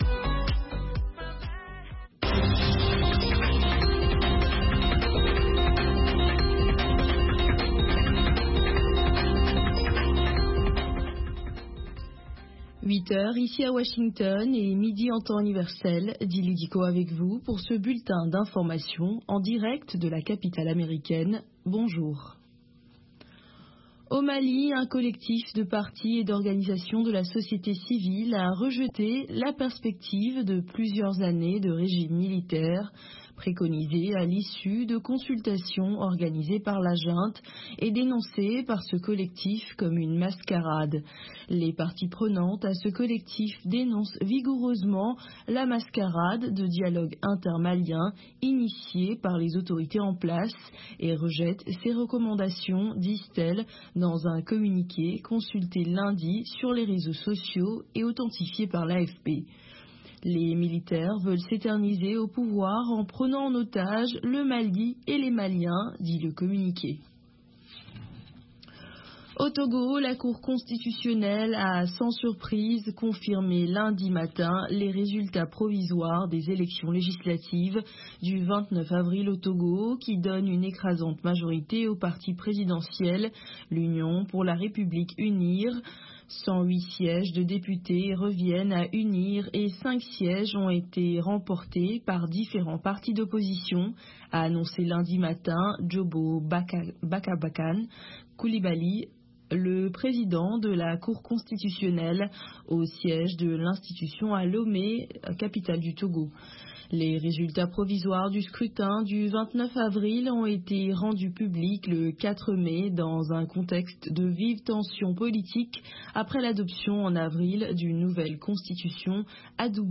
Bulletin d'information de 16 heures
Bienvenu dans ce bulletin d’information de VOA Afrique.